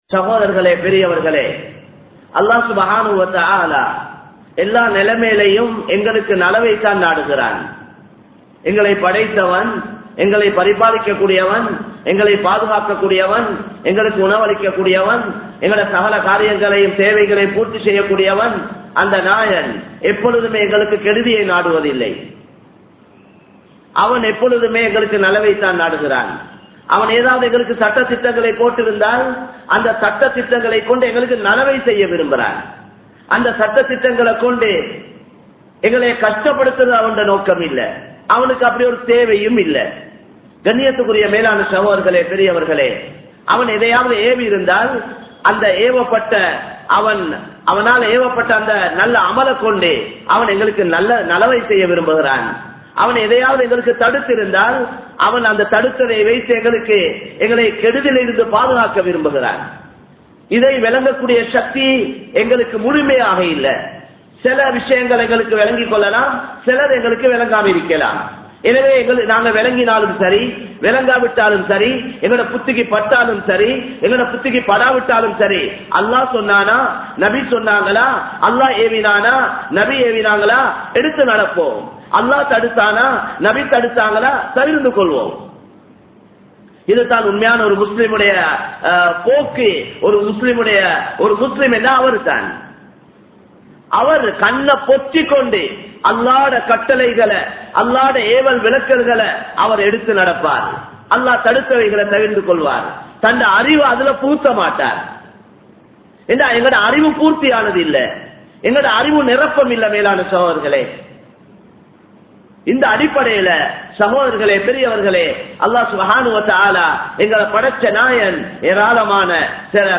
Allahvin Kattalaihalai Purakkanikkatheerhal (அல்லாஹ்வின் கட்டளைகளை புறக்கனிக்காதீர்கள்) | Audio Bayans | All Ceylon Muslim Youth Community | Addalaichenai
Samman Kottu Jumua Masjith (Red Masjith)